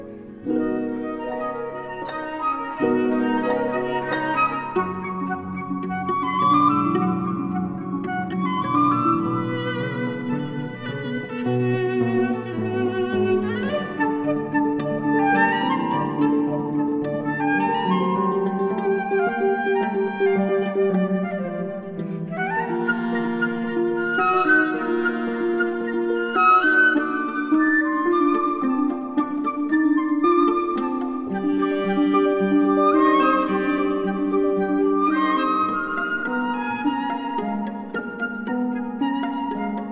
豎琴、長笛、中提琴